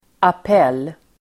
Ladda ner uttalet
Uttal: [ap'el:]